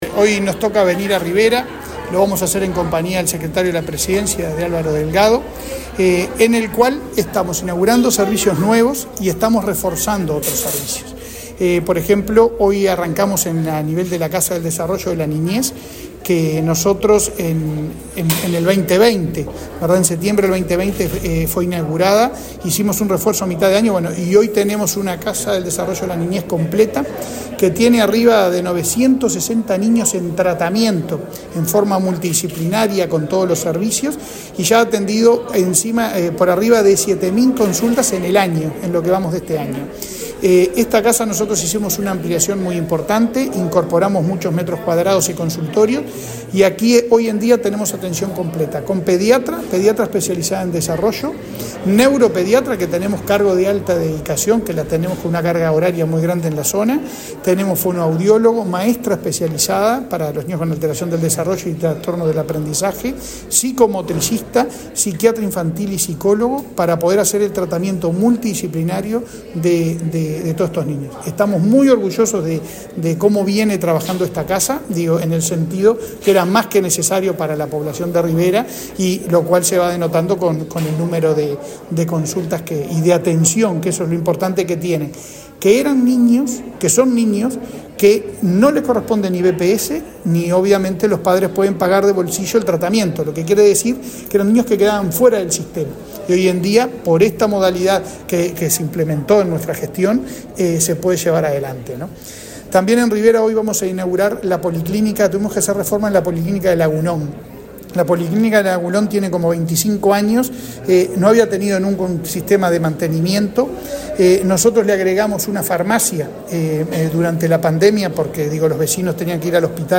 Entrevista al presidente de ASSE, Leonardo Cipriani
El presidente de ASSE, Leonardo Cipriani, dialogó con Comunicación Presidencial en Rivera, durante una recorrida que el jerarca realizó por ese